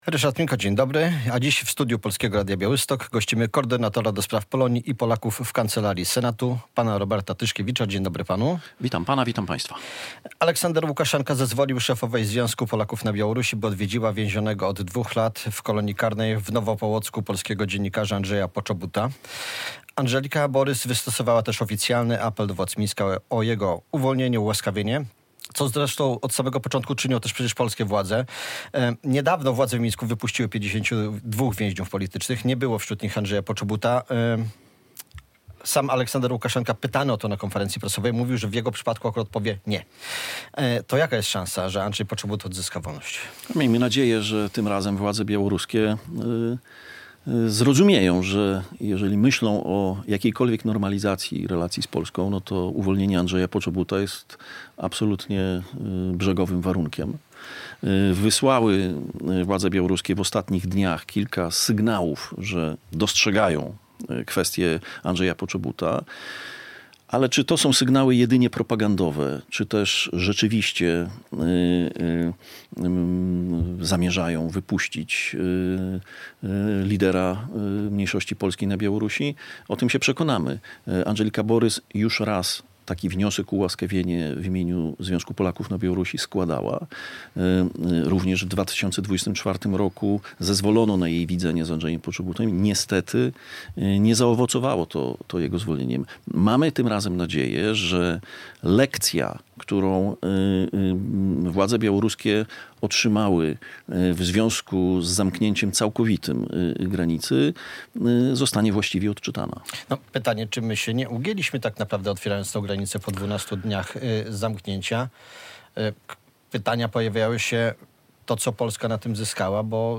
Radio Białystok | Gość | Robert Tyszkiewicz - koordynator do spraw Polonii i Polaków za granicą w kancelarii Senatu RP